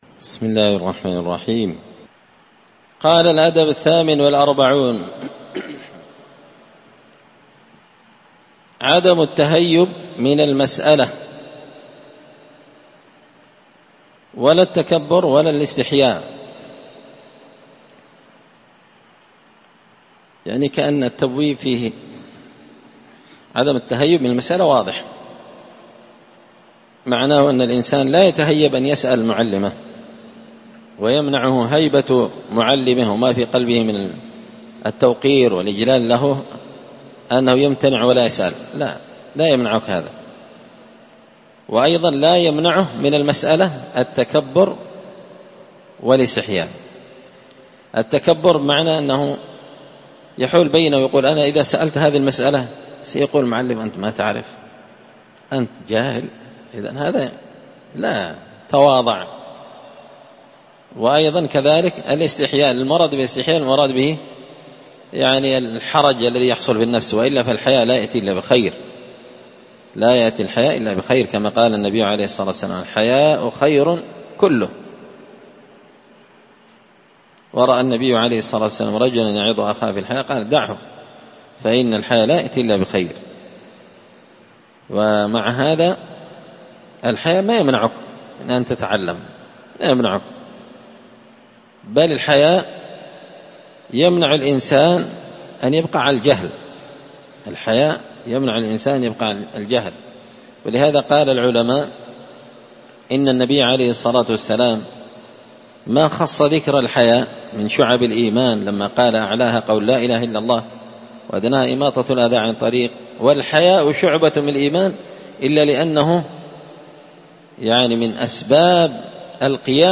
الدرس السادس والخمسون (56) الأدب الثامن والأربعون عدم التهيب من المسألة ولا التكبر ولا الاستحياء
مسجد الفرقان قشن_المهرة_اليمن